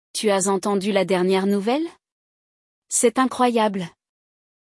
Neste episódio, acompanhamos uma conversa entre dois colegas de trabalho, que estão trocando informações e, claro, comparando situações e pessoas.